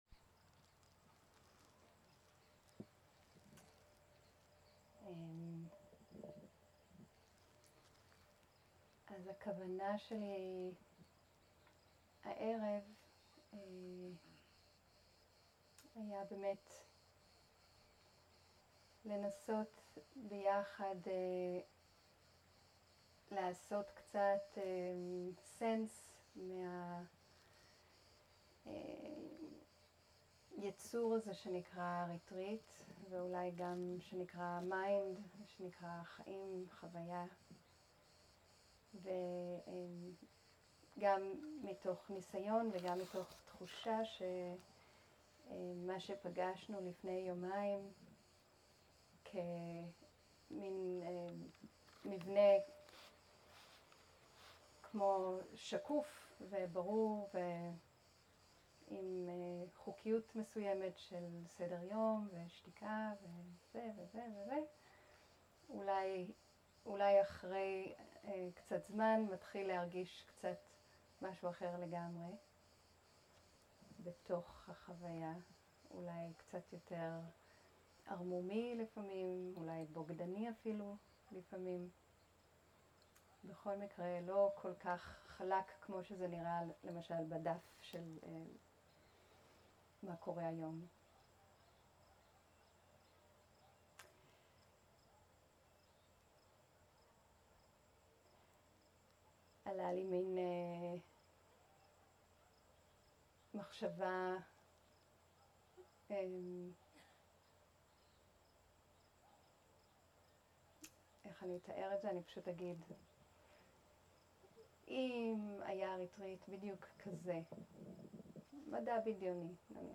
שיחת דהרמה
סוג ההקלטה: שיחות דהרמה
איכות ההקלטה: איכות גבוהה